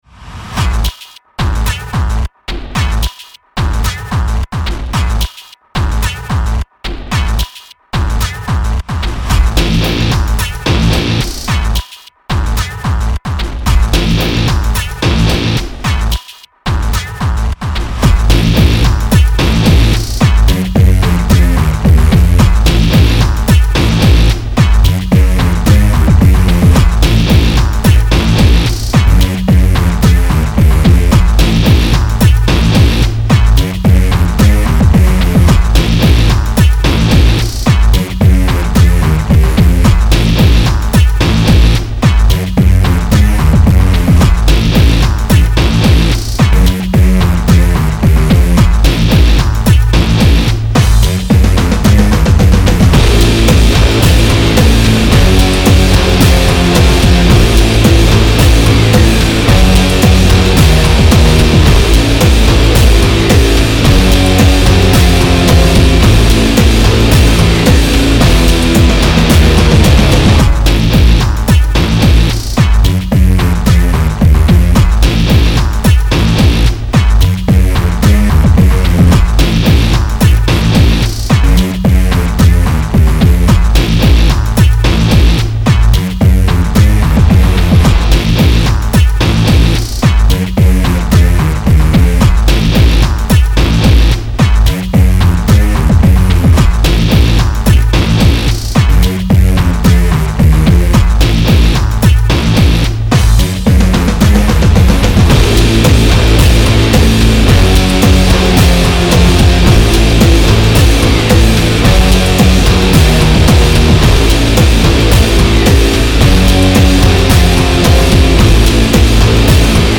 Very raw, I like it.